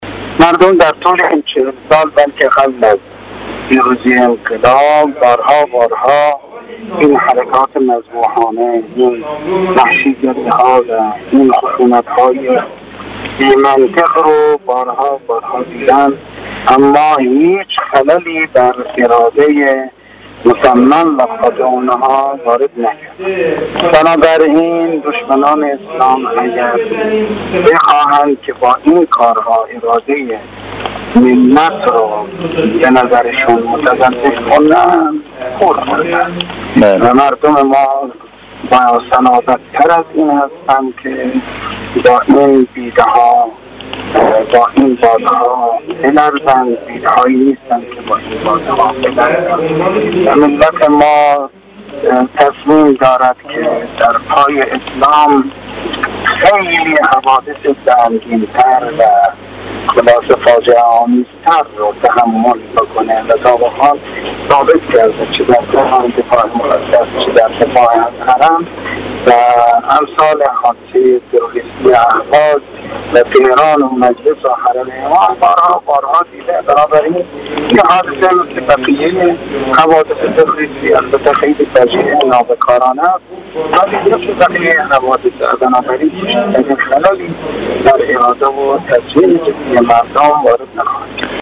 آیت الله حیدری در گفت وگو با رسا: